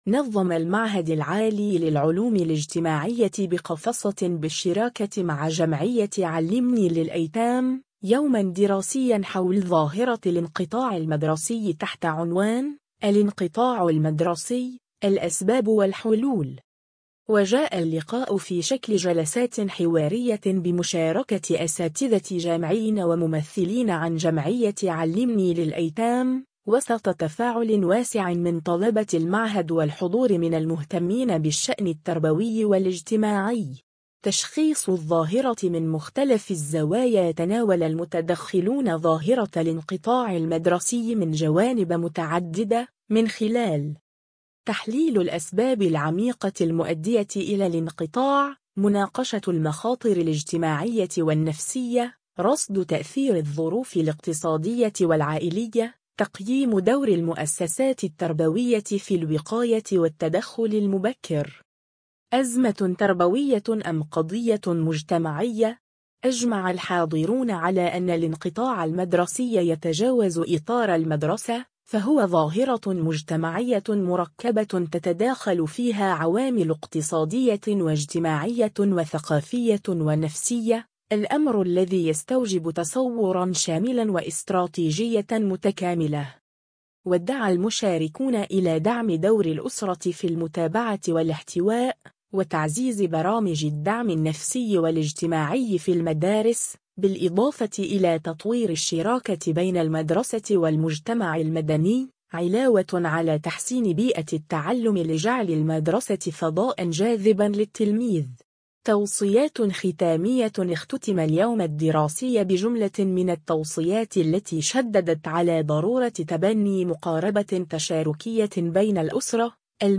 وجاء اللقاء في شكل جلسات حوارية بمشاركة أساتذة جامعيين وممثلين عن جمعية “علّمني للأيتام”، وسط تفاعل واسع من طلبة المعهد والحضور من المهتمين بالشأن التربوي والاجتماعي.